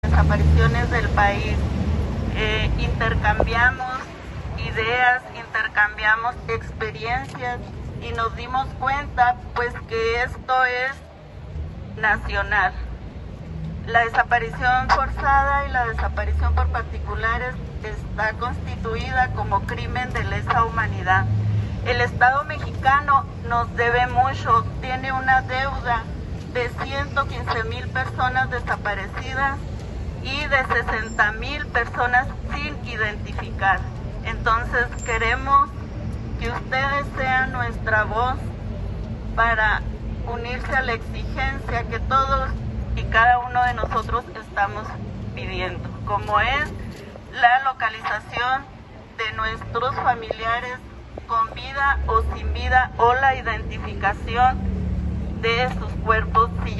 AUDIO: MANIFESTANTE
MANIFESTANTE.mp3